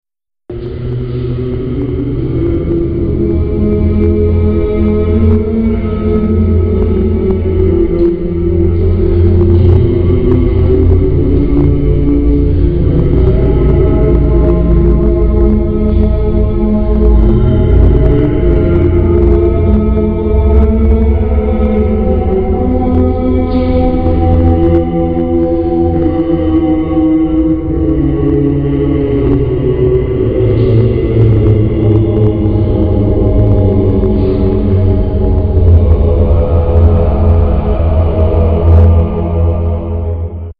Besonders beeindruckt waren wir von der Akustik der Bartholomäus-Kapelle, ca. 1017 erbaut von vermutlich griechischen Bauleuten. Sicher jedoch können wir bezeugen, dass die dortige Akustik bässtens ist:
(635 KB, 40 Sekunden, mp3-File) - kurzer Mitschnitt unserer bassigen Experimente
Die Aufnahmen sind mit einer digitalen Fotokamera gemacht mit der man auch Videos erzeugen kann. Die Qualität ist leider sehr schlecht, doch man bekommt trotzdem einen sehr guten Eindruck von der unglaublichen Akustik dieser kleinen Kapelle im Herzen Paderborns.
kapelle.mp3